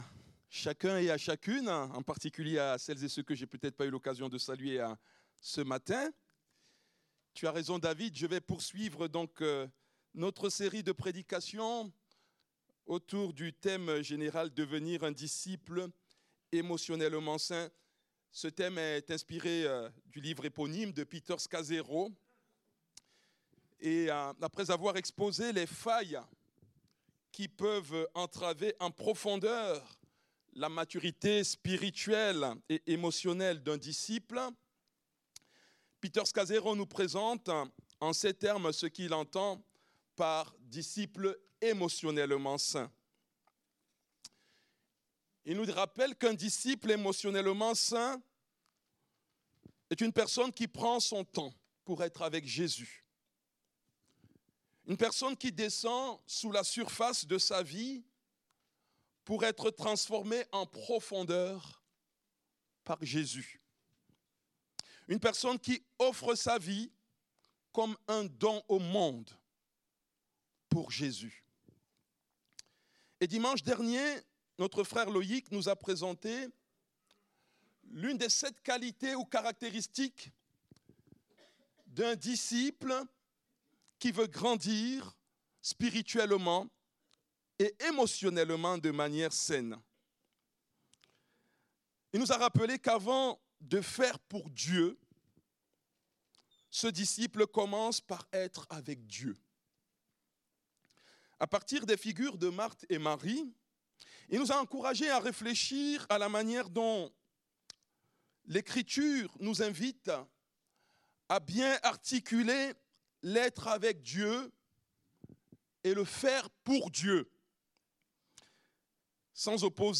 Culte du dimanche 16 février